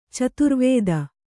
♪ caturvēda